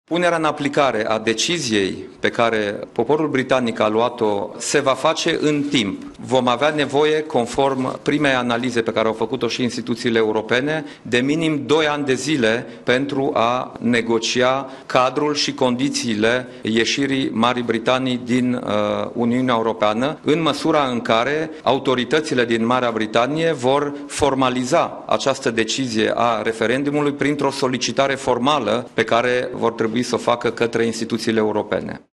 Premierul Dacian Cioloş a declarat, într-o conferinţă de presă la Palatul Victoria, că ieşirea Marii Britanii din UE nu va avea un impact semnificativ, pe termen scurt, asupra economiei şi că românii care muncesc în Regatul Unit nu vor fi afectaţi în drepturile pe care le au în calitate de cetăţeni europeni.